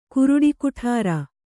♪ kuruḍi kuṭhāra